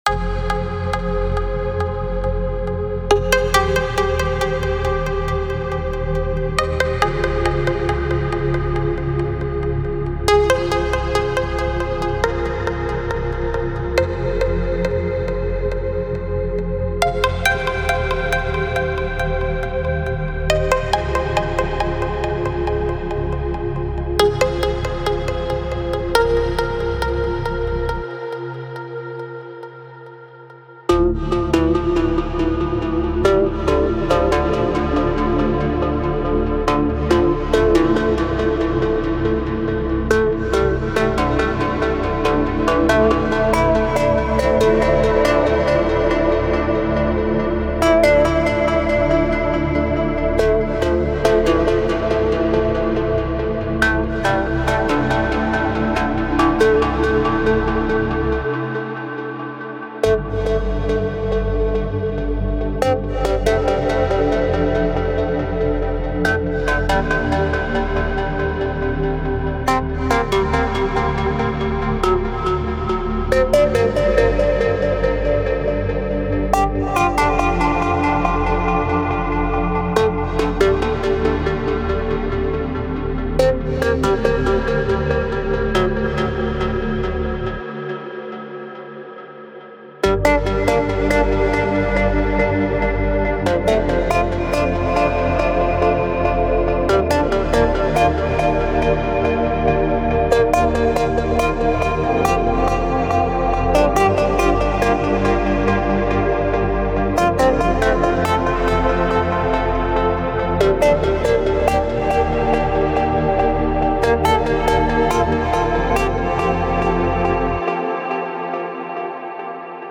House Melodic Techno Progressive House Trance
30 MIDI’s in total ( Melody, Pad, Break Bass for any Kit )